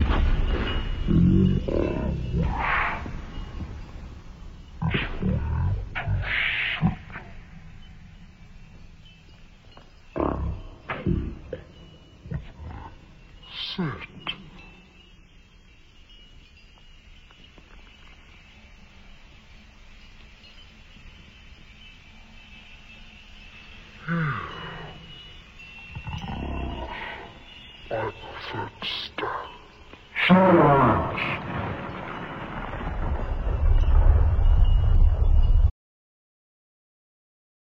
For all experiments, I used a sound clip from one of my favorite movies “Legend” where Tim Curry plays the devil, and Tom Cruise and Mia Sara are the main characters fighting him.
And here’s an even slower / lower pitched version (210% of time):
out_a_slowerlower.mp3